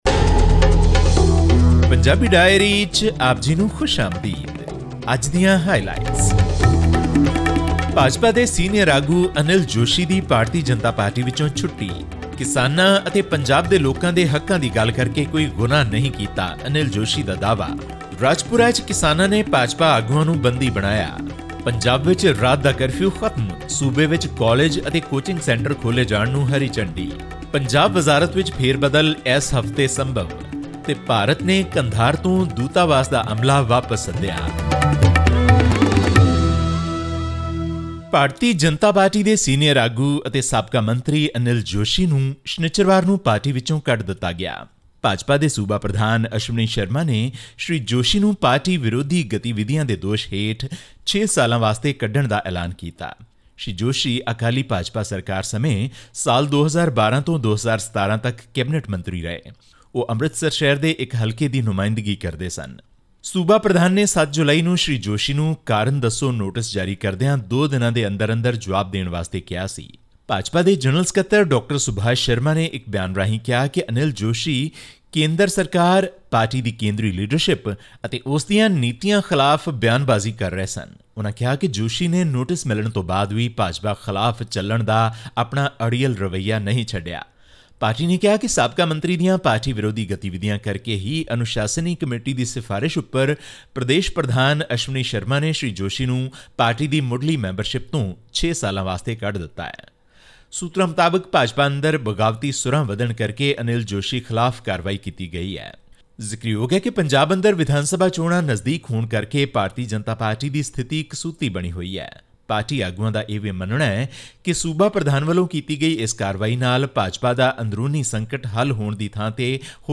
Punjab’s Bharatiya Janata Party unit expelled rebel leader Anil Joshi for six years on 10 July over his alleged criticism of the central government's policies. This and more from our weekly news bulletin from Punjab.